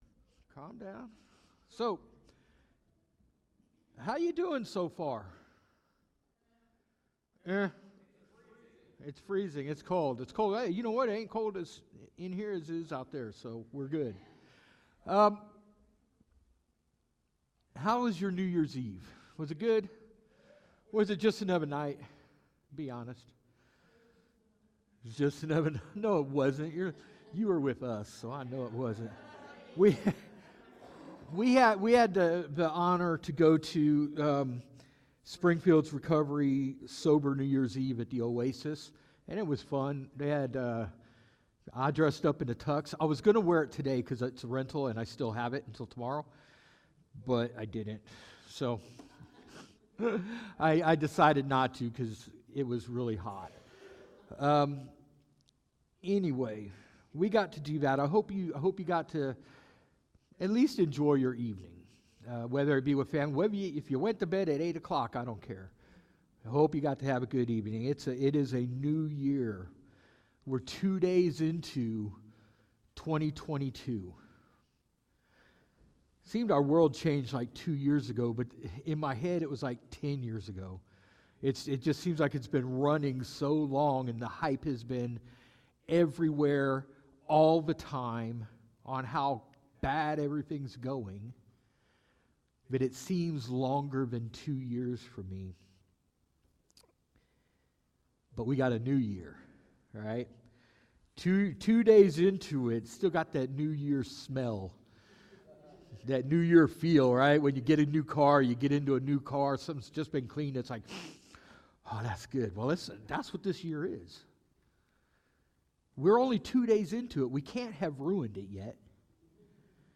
Sermon
NOTE – This sermon recording is only audio.